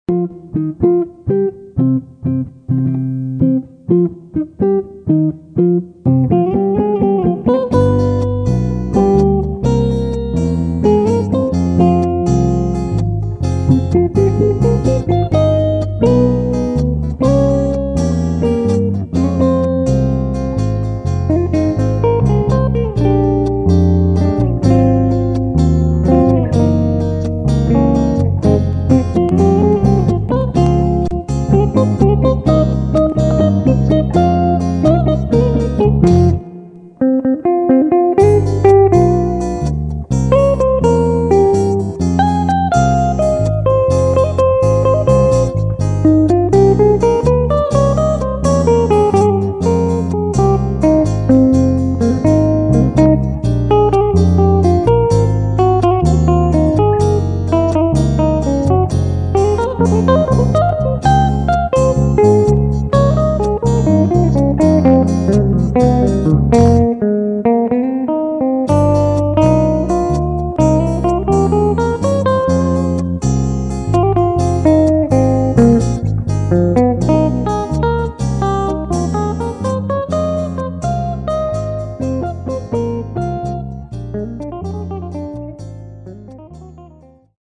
Bossa-beguine.